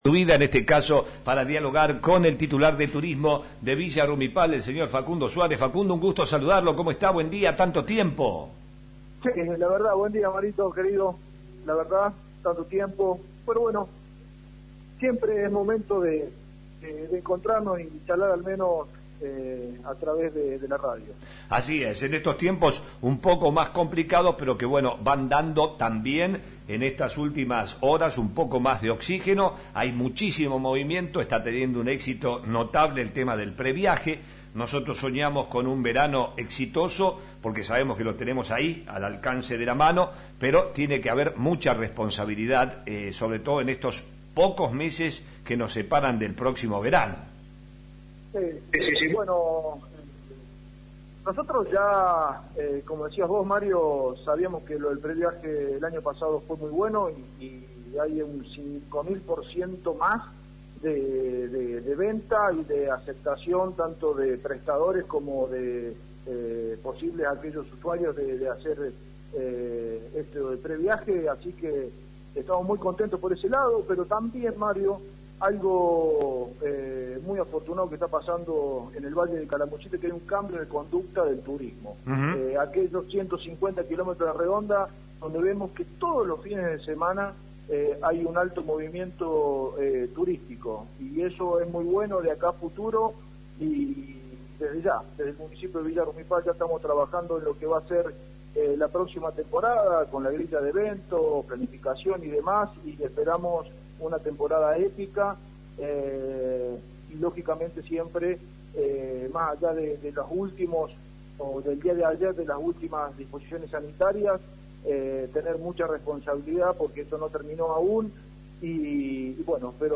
Facundo Suárez, Secretario de Turismo de Villa Rumipal habló hoy en flash FM para adelantar detalles de lo que se organiza con mucho optimismo para la próxima temporada. Suárez confirmó que el intendente Gantus se manifestó en contra de la instalación de un crematorio en Villa del Dique.